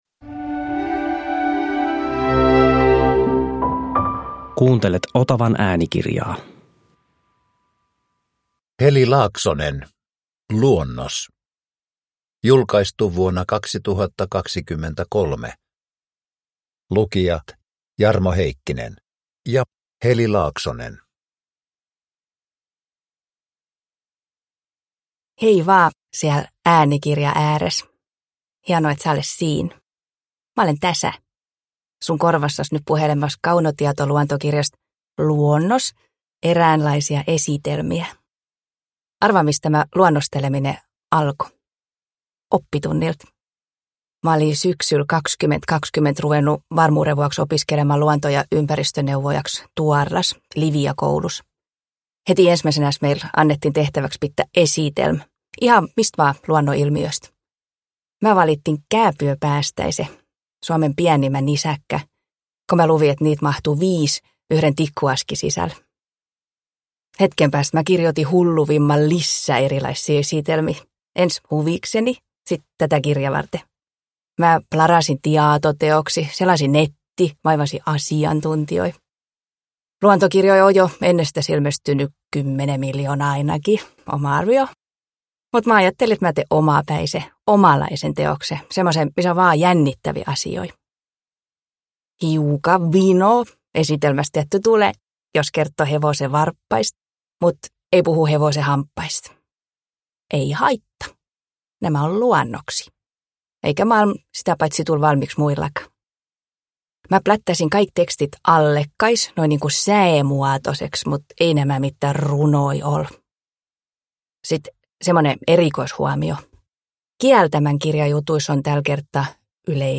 Luonnos – Ljudbok